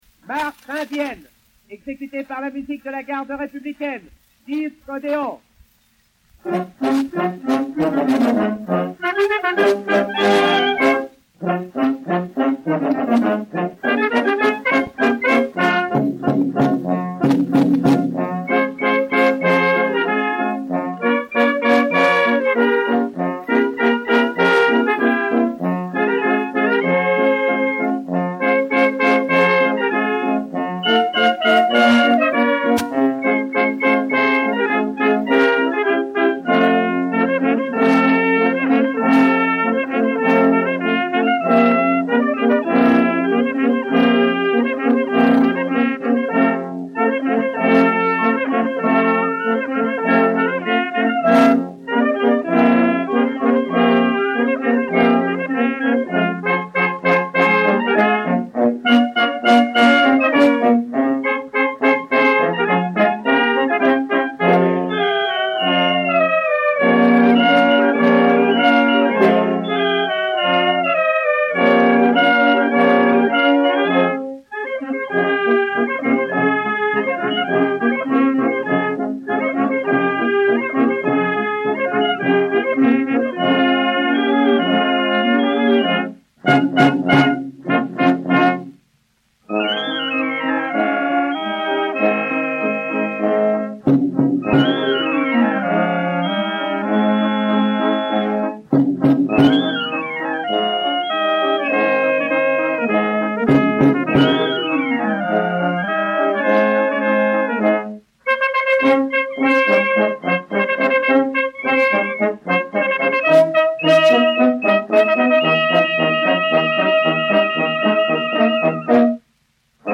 Musique de la Garde Républicaine
Odéon 33045, enr. à Paris vers 1910